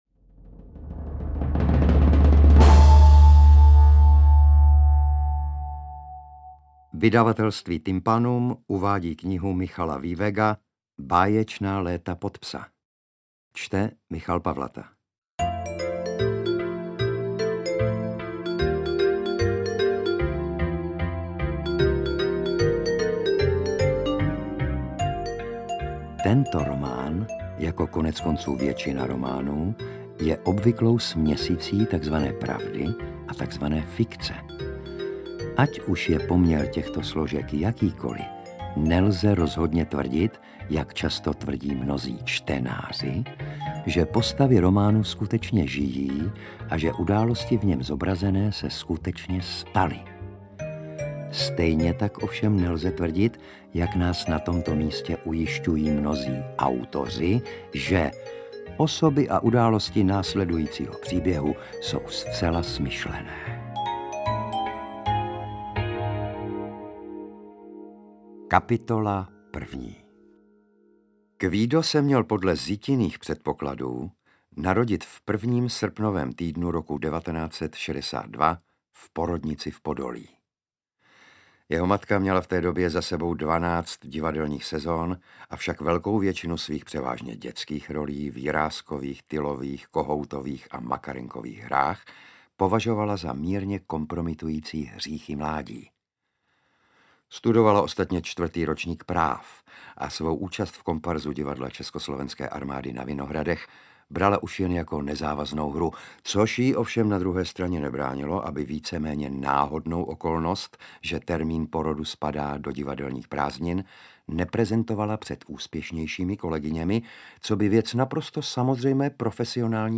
Interpret:  Michal Pavlata
Nyní poprvé vychází jako nezkrácená audiokniha.